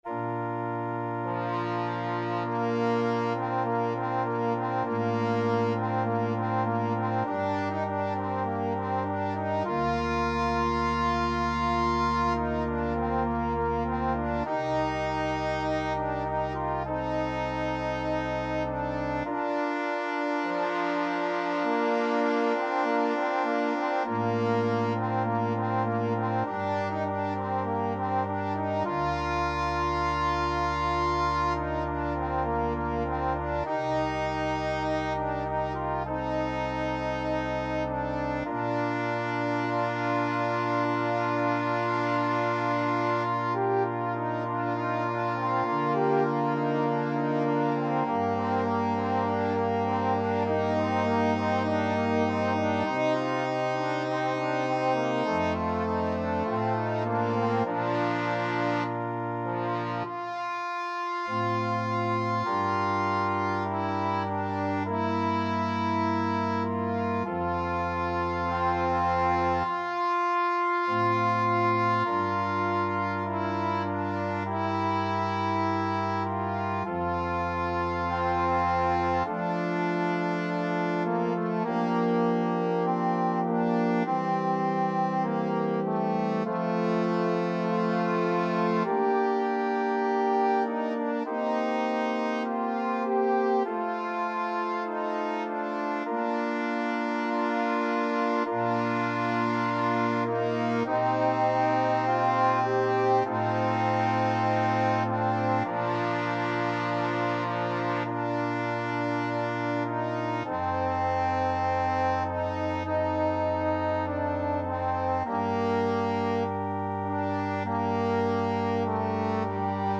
Free Sheet music for Trombone Duet
Bb major (Sounding Pitch) (View more Bb major Music for Trombone Duet )
Largo
Trombone Duet  (View more Intermediate Trombone Duet Music)
Classical (View more Classical Trombone Duet Music)